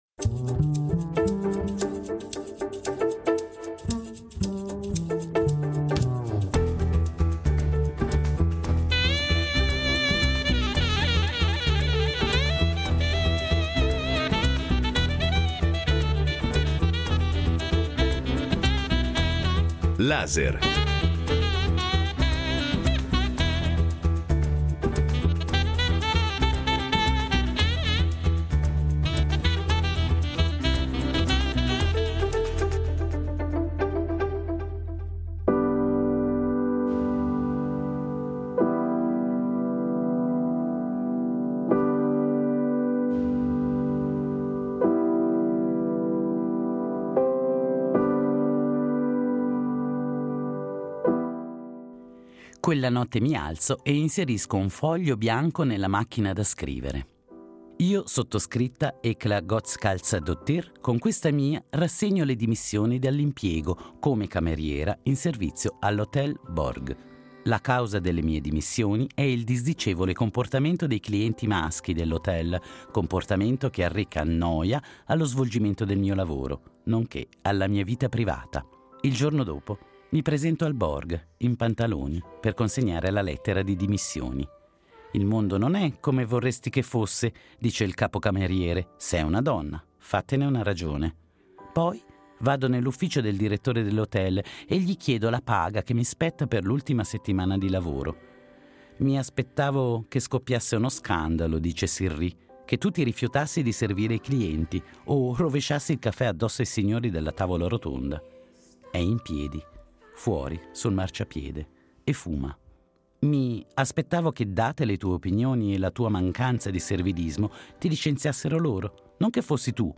Incontro con la scrittrice islandese Auður Ava Ólafsdóttir